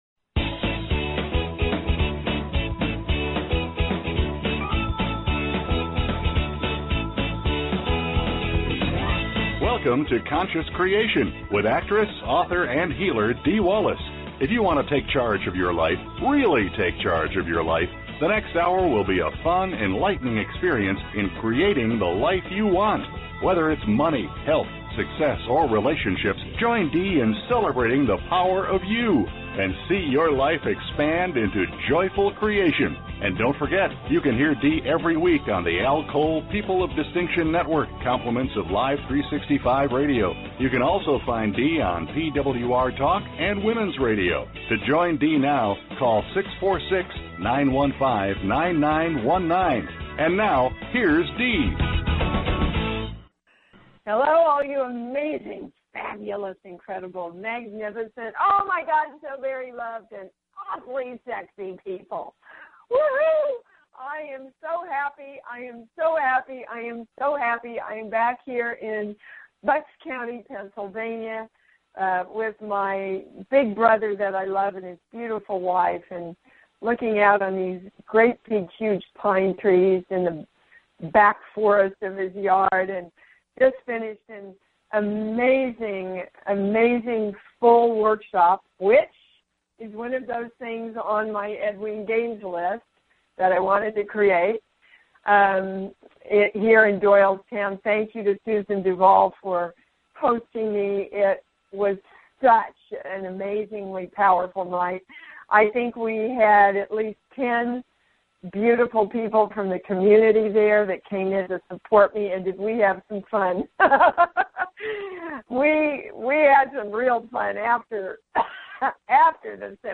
Talk Show Episode, Audio Podcast, Conscious Creation and with Dee Wallace on , show guests , about Core Truths,Balanced Life,Energy Shifts,Spirituality,Spiritual Archaeologist,Core Issues,Spiritual Memoir,Healing Words,Consciousness,Self Healing, categorized as Health & Lifestyle,Alternative Health,Energy Healing,Kids & Family,Philosophy,Psychology,Self Help,Spiritual,Psychic & Intuitive